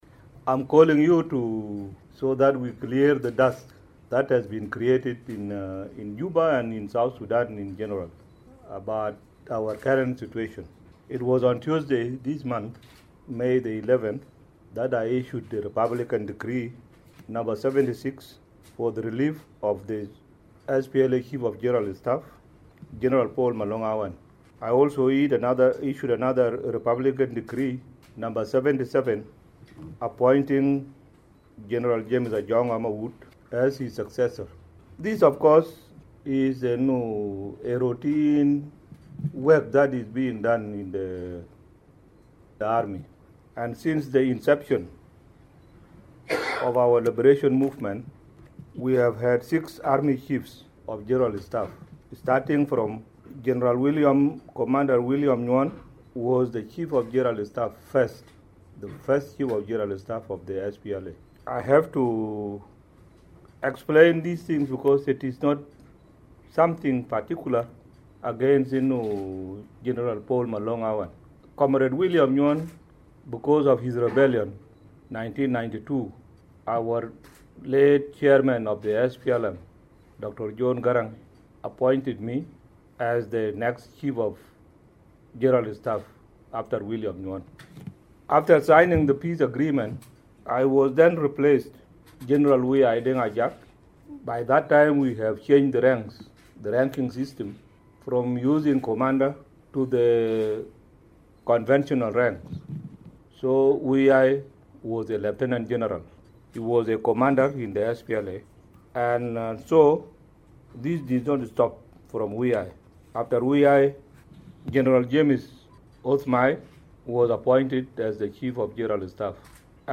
President Salva Kiir has spoken to reporters in Juba, two days after he sacked the chief of General staff Paul Malong. He assured the nation that the security remains normal.